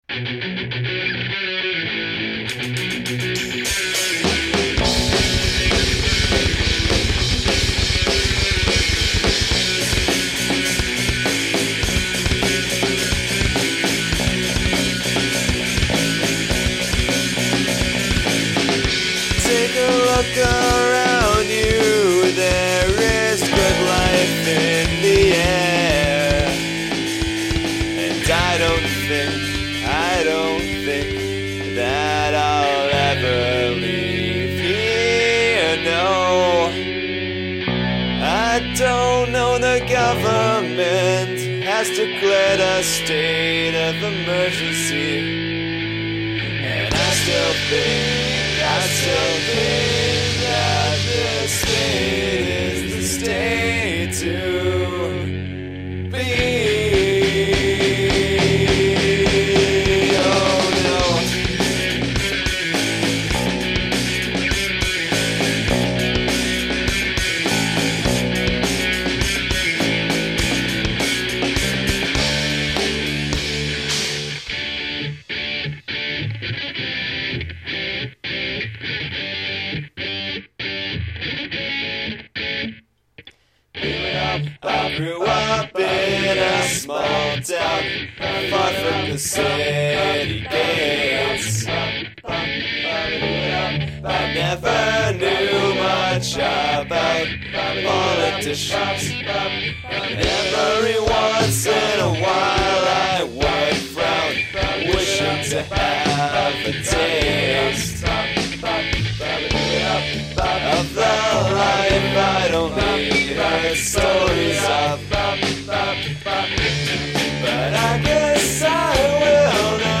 Guest Vocals